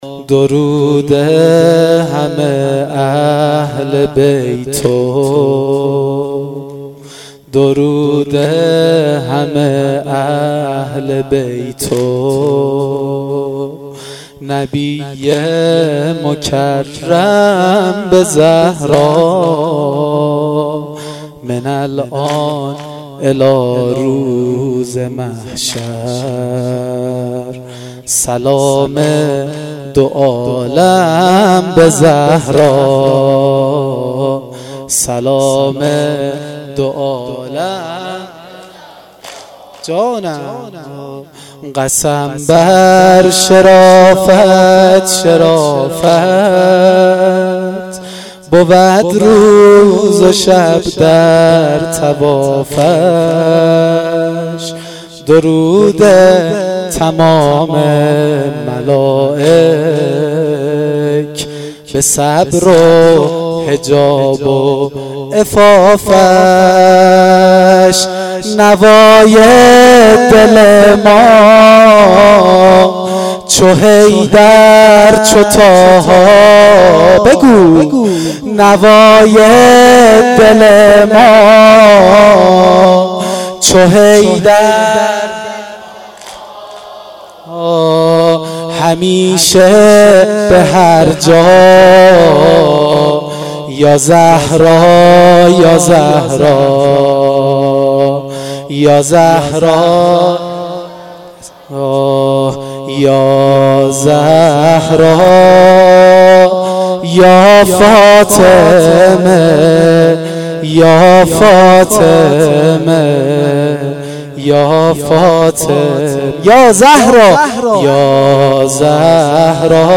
واحد سنگین شب اول فاطمیه